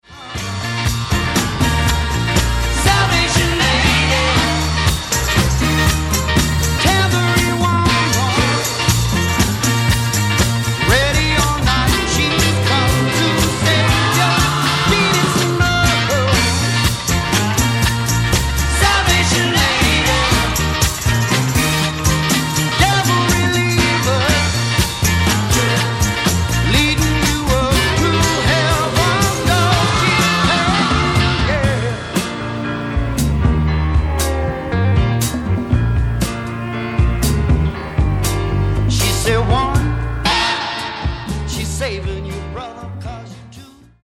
SWAMP ROCK